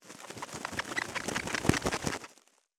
651ゴミ袋,スーパーの袋,袋,
効果音